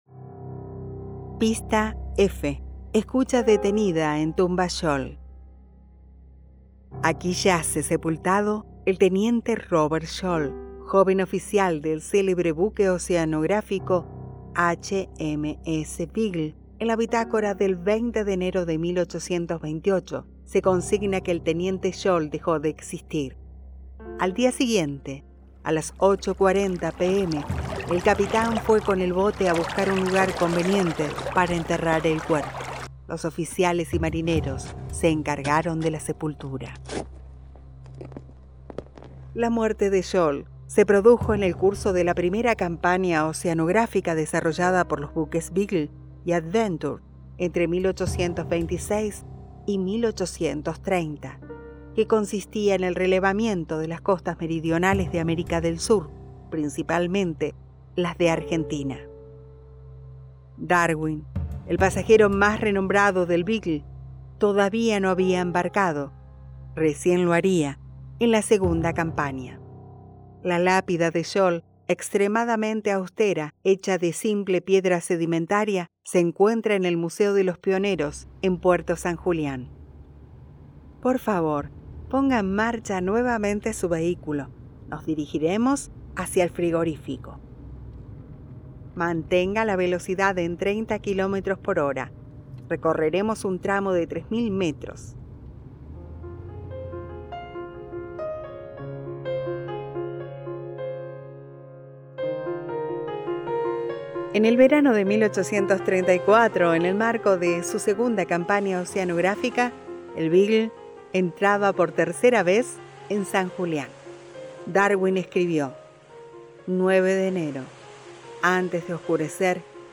Además, y para que esta propuesta sea más amena, incluimos también producciones musicales de nuestros artistas, que ilustran el paisaje y dan color al viaje.
Audioguía Vehicular Huelgas Patagónicas